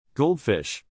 armchair / ARM-chair